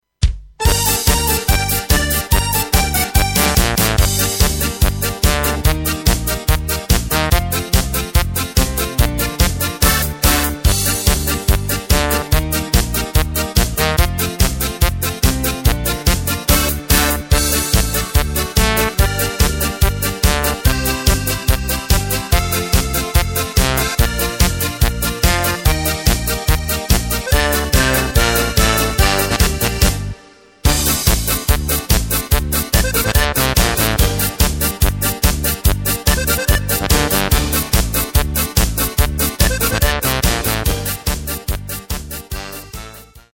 Takt:          4/4
Tempo:         144.00
Tonart:            Gm
Schlager-Polka (Cover) aus dem Jahr 2020!
Playback mp3 Demo